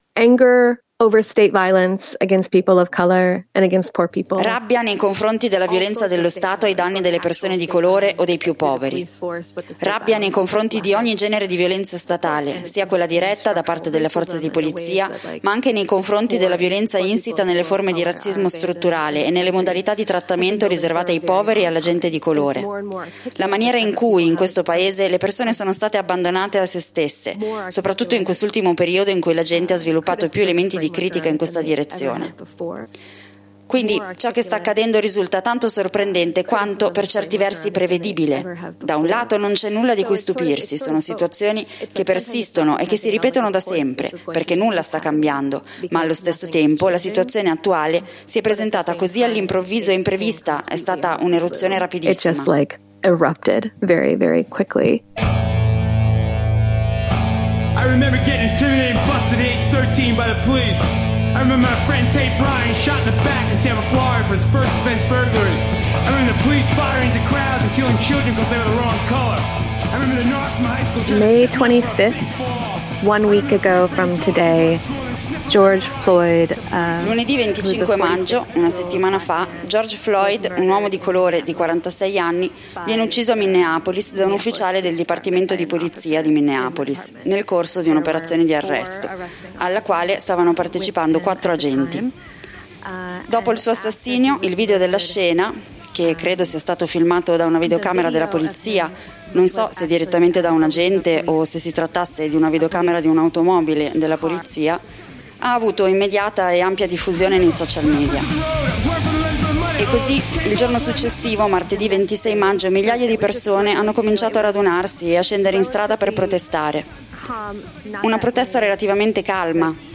La tempesta perfetta: una corrispondenza da Minneapolis
Con una compagna di Minneapolis ripercorriamo, relativamente a caldo, quanto è successo dal 25 maggio 2020 in Minnesota, dopo l’uccisione di George Floyd.